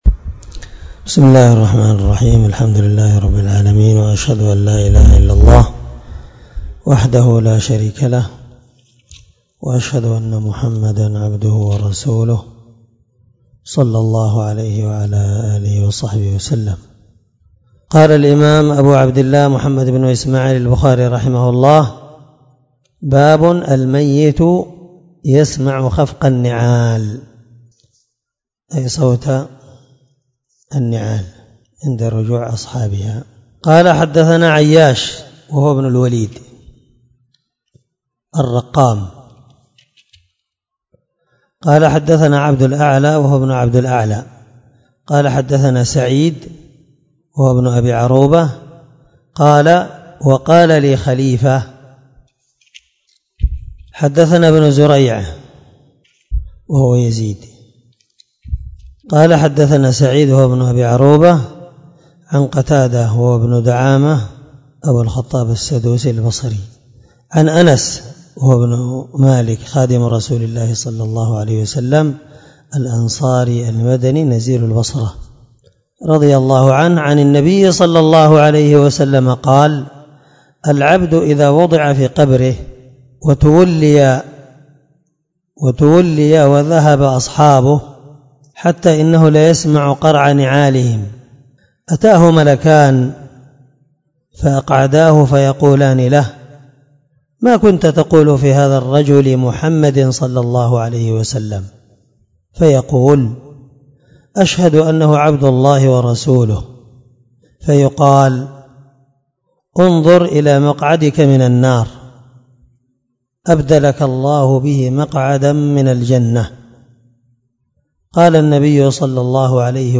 772الدرس 45من شرح كتاب الجنائز حديث رقم(1338 )من صحيح البخاري
دار الحديث- المَحاوِلة- الصبيحة.